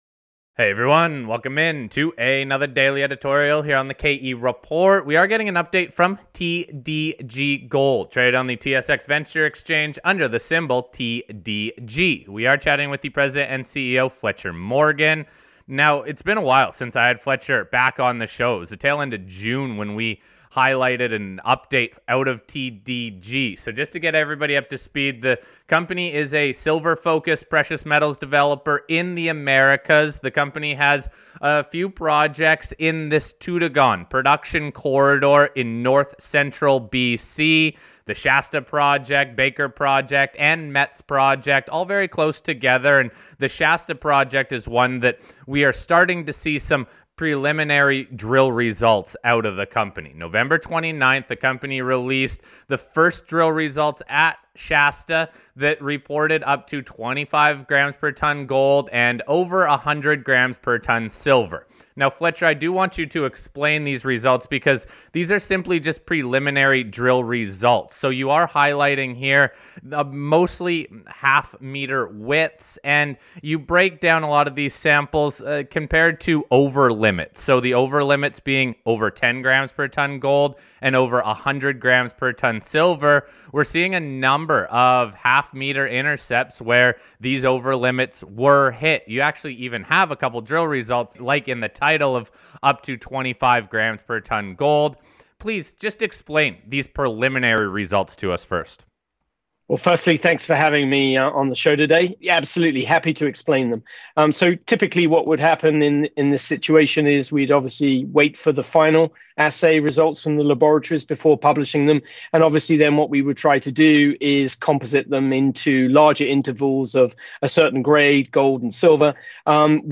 To wrap up the interview we get an update on the Nueva Esperanza Silver-Gold Project acquisition.